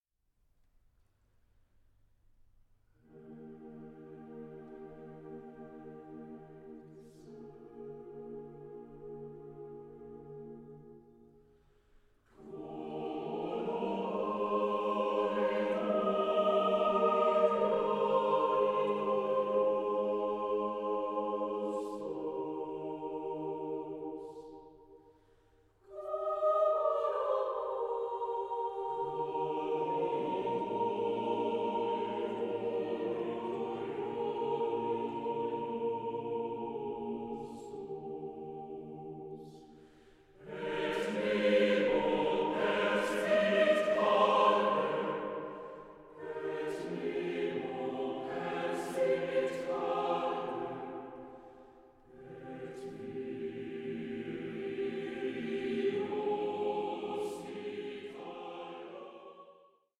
Graduale
TWO MASTERS OF AUSTRIAN CHURCH MUSIC COMBINED